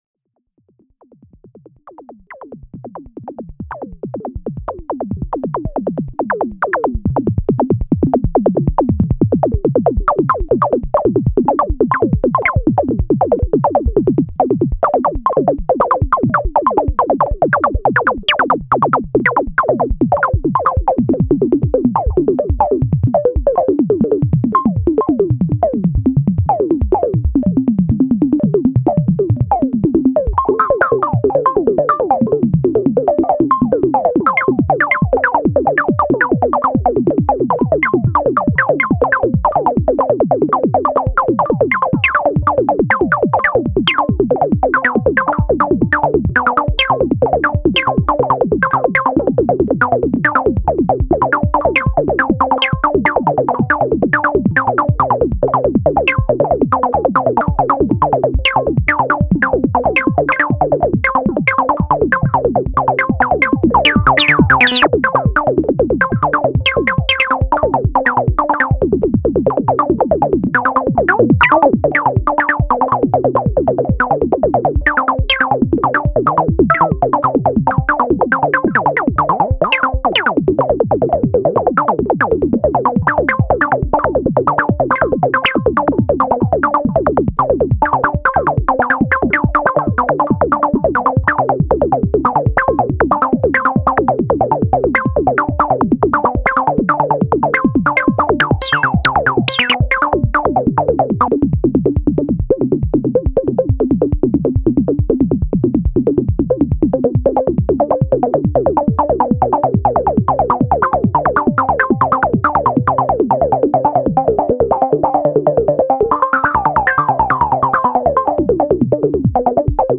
This is a sound made with my music equipment.
Genure asid techno
Roland JUPITER-4 Synthesizer / Voltage-Controlled Oscillator